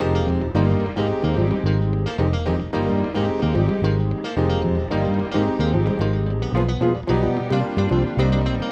13 Backing PT1.wav